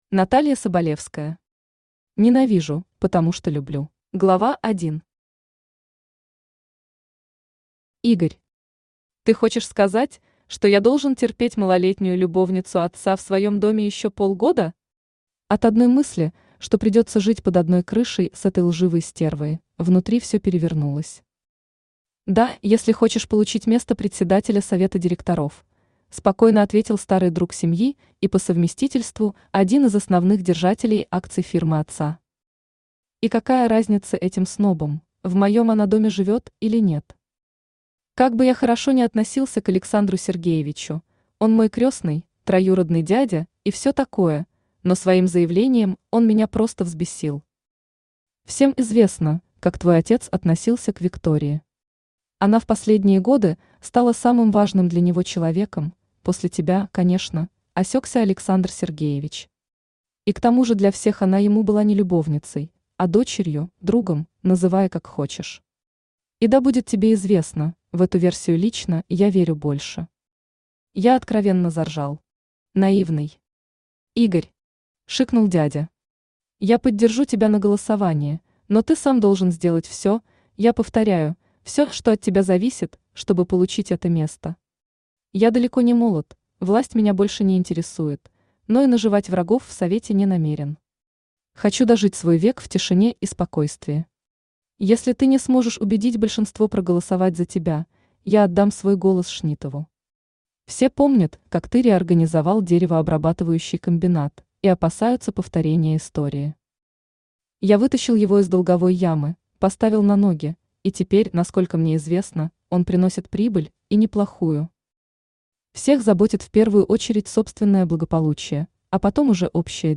Аудиокнига Ненавижу, потому что люблю | Библиотека аудиокниг
Aудиокнига Ненавижу, потому что люблю Автор Наталья Соболевская Читает аудиокнигу Авточтец ЛитРес.